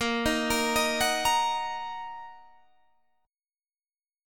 Bbsus4#5 chord